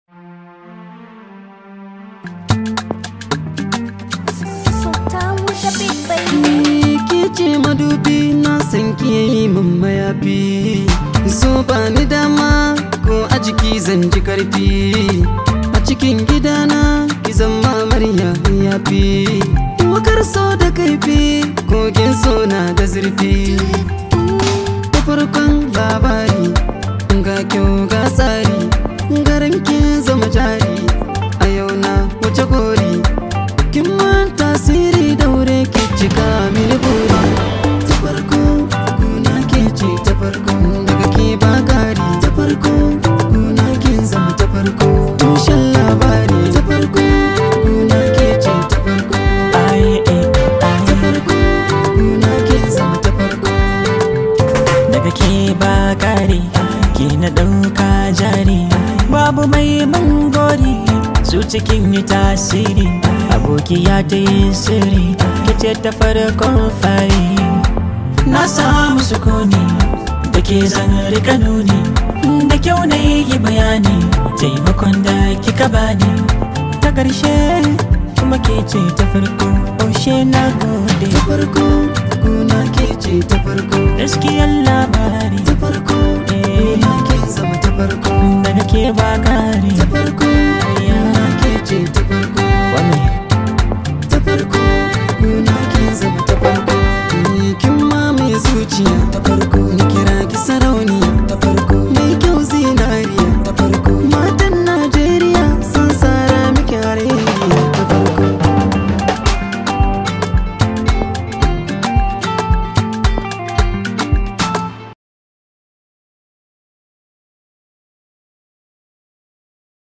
Hausa Singer